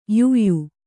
♪ yuyu